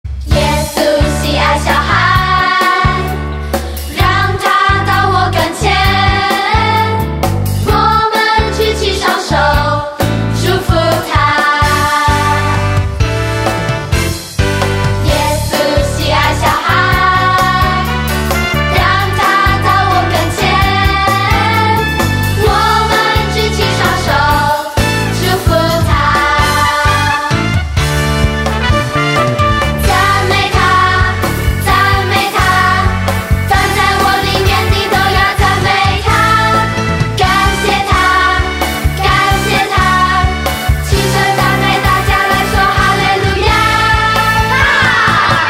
全部商品 / 音樂專區 / 中文專輯 / 兒童敬拜
12首充滿活力與感動的敬拜讚美+ 7首傳遞堅定愛神的精彩MV
聽見孩子真摯的歌聲 可以改變您的心情  看見神所創造的美好